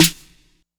Snares
STE_TRP_SNR (2).wav